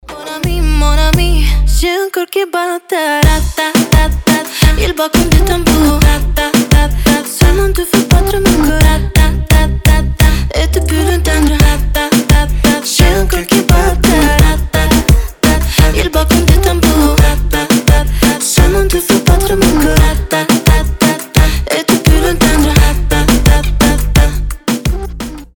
• Качество: 320, Stereo
ритмичные
заводные
dancehall
дуэт